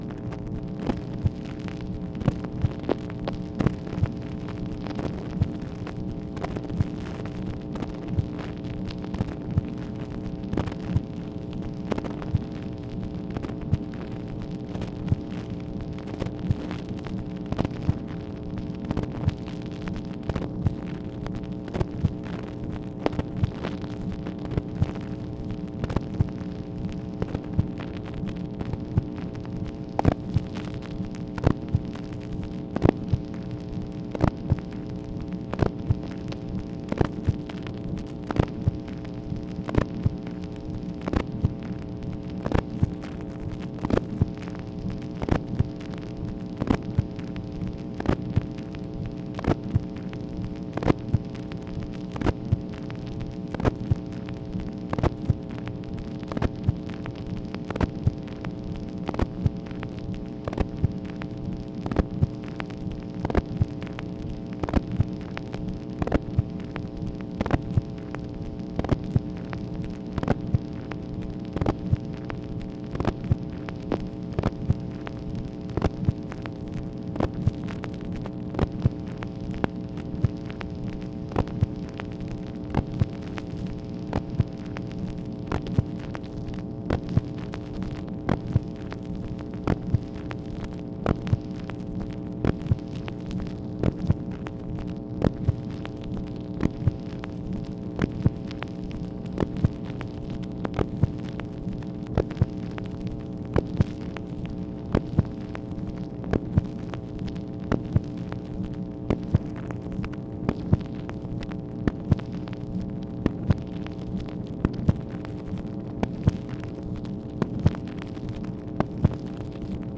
MACHINE NOISE, August 4, 1964
Secret White House Tapes | Lyndon B. Johnson Presidency